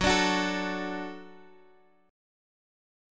F#m#5 chord